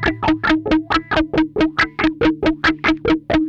SLO TICK.wav